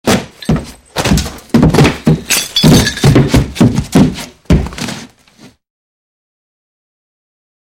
Звуки падения по лестнице
Звуки падения тела с лестницы: полет вниз и удары о ступени